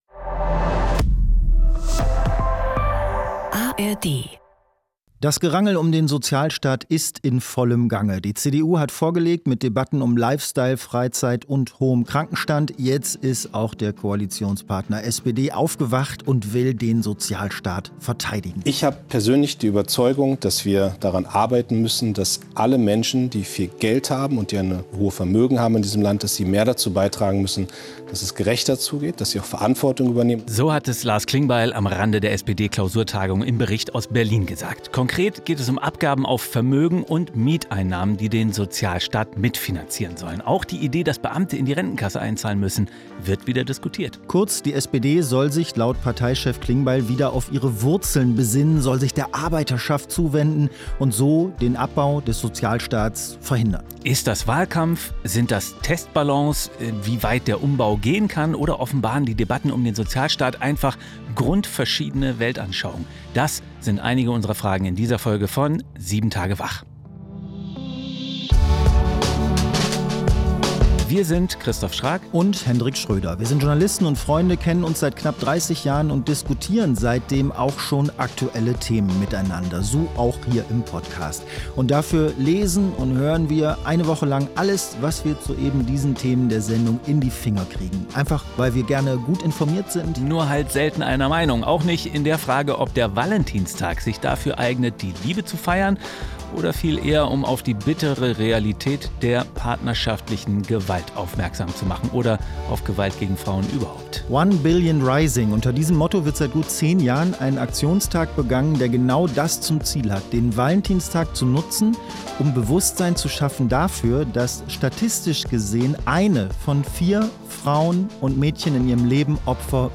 Zwei Freunde, zwei Meinungen, ein News-Podcast: